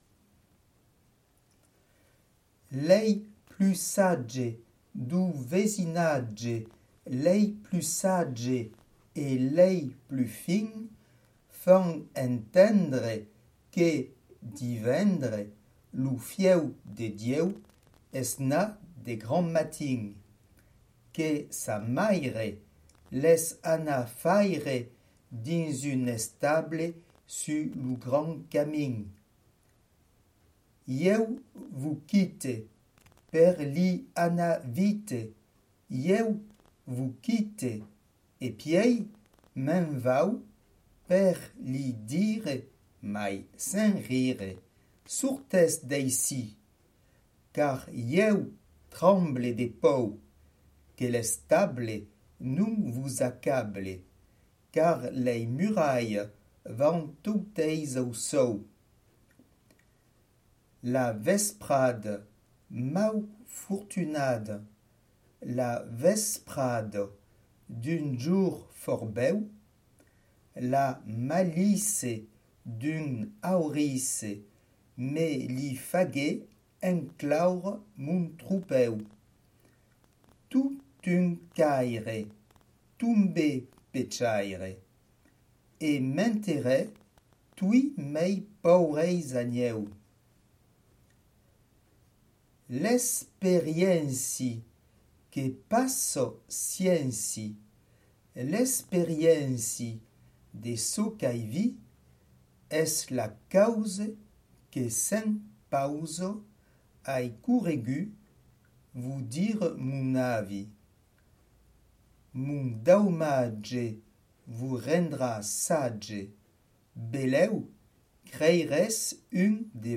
La prononciation du provençal  ne se fait pas par la lecture  moderne du texte, suivre l'exemple donné.